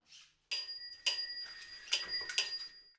Fahrradglocke
Geräusch einer Fahrradklingel
fahrrad